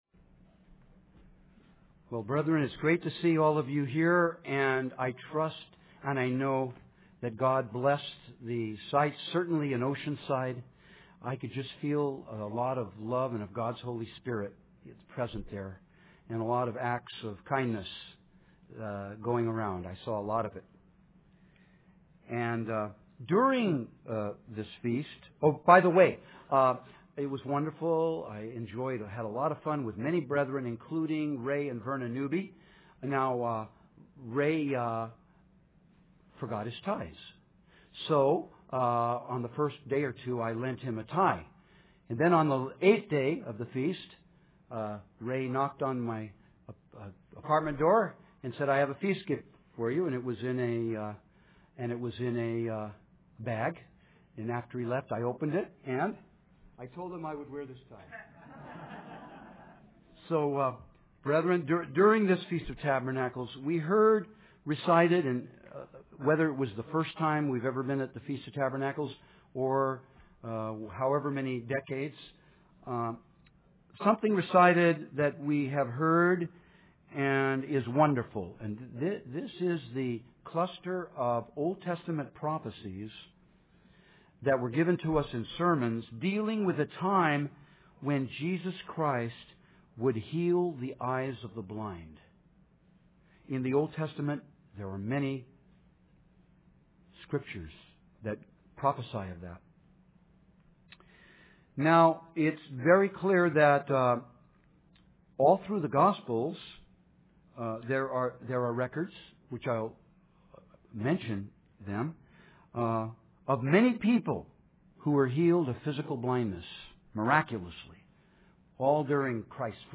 Given in San Francisco Bay Area, CA
UCG Sermon Studying the bible?